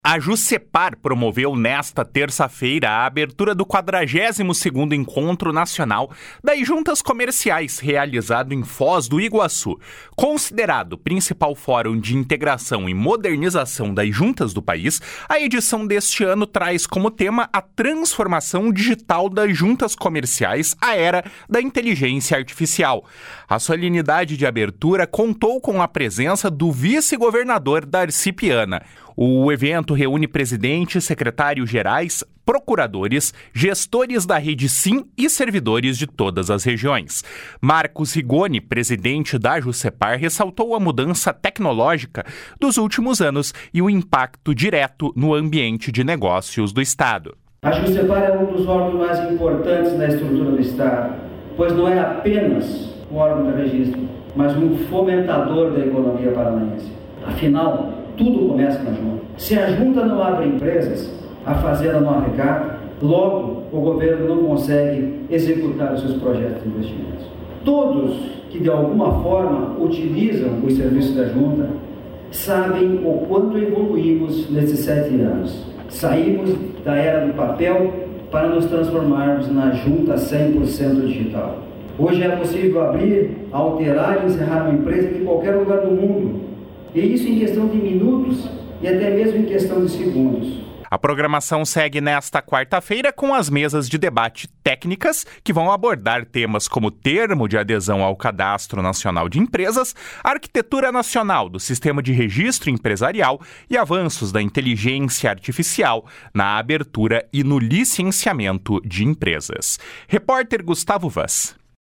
Marcos Rigoni, presidente de Jucepar, ressaltou a mudança tecnológica dos últimos anos e o impacto direto no ambiente de negócios do Estado. // SONORA MARCOS RIGONI //